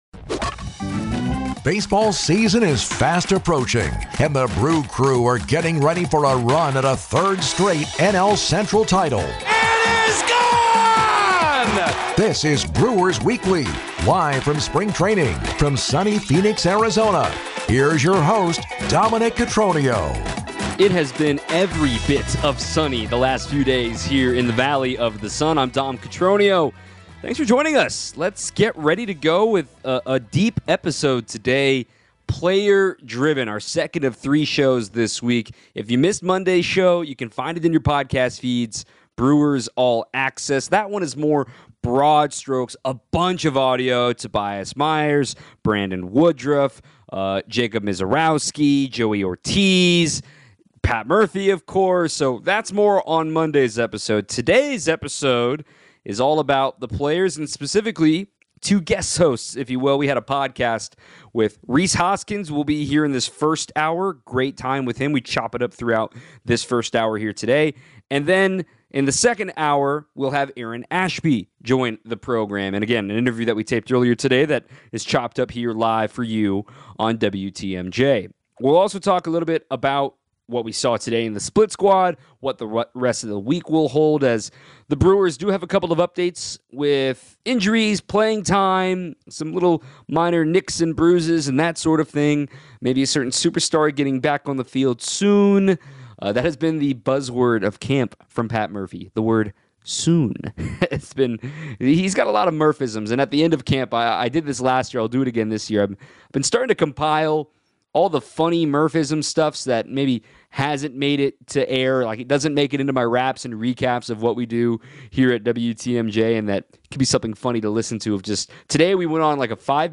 Brewers All Access - Brewers Weekly LIVE from Spring Training: Episode 2